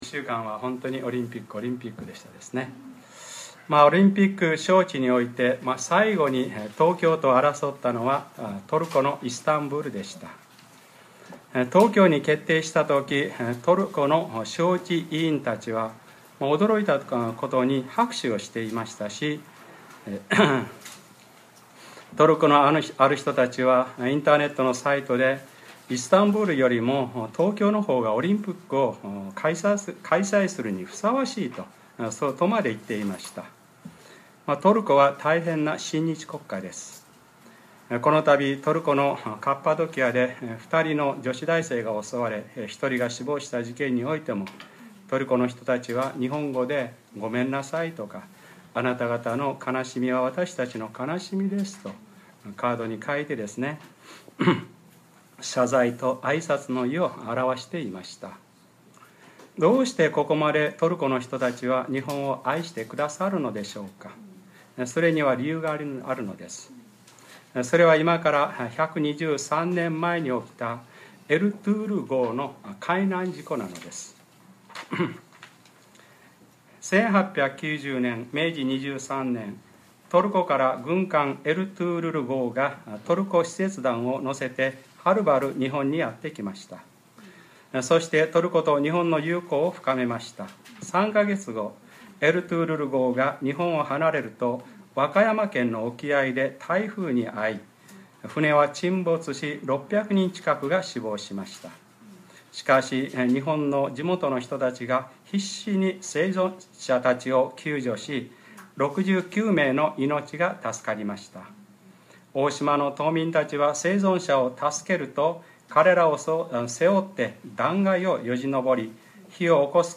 2013年9月15日(日）礼拝説教 『真理はあなたを自由にします』